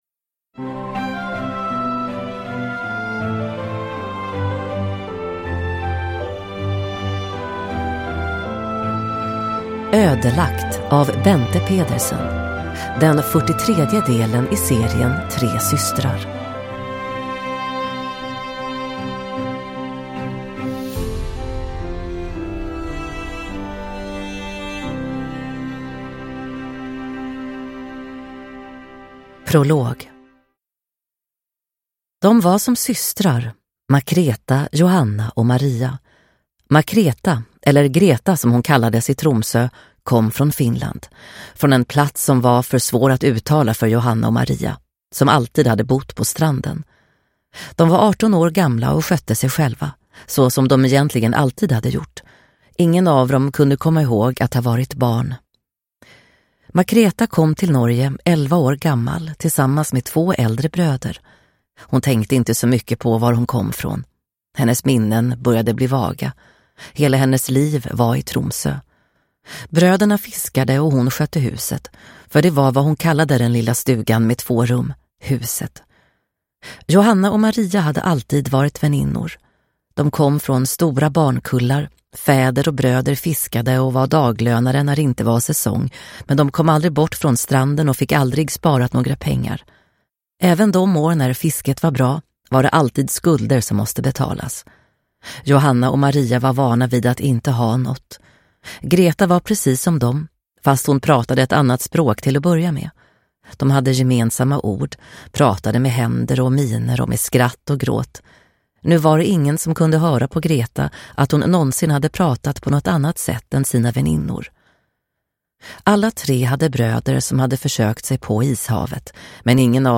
Ödelagt – Ljudbok